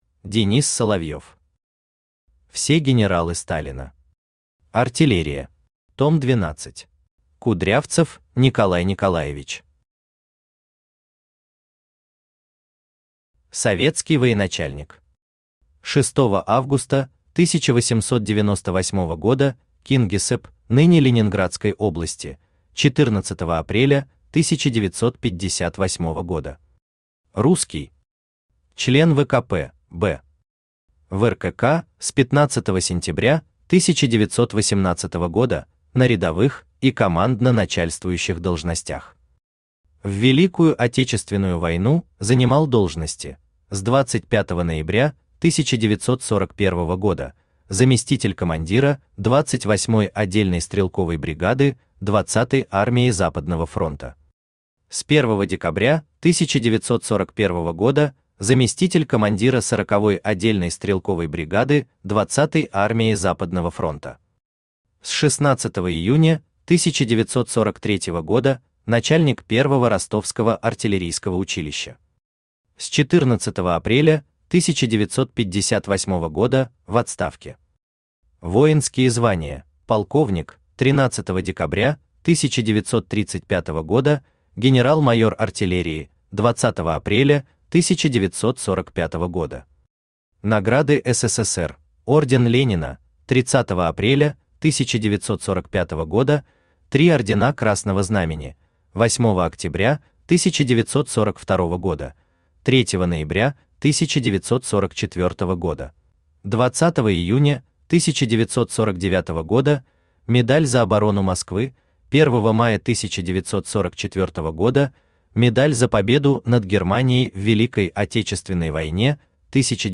Аудиокнига Все генералы Сталина. Артиллерия. Том 12 | Библиотека аудиокниг
Том 12 Автор Денис Соловьев Читает аудиокнигу Авточтец ЛитРес.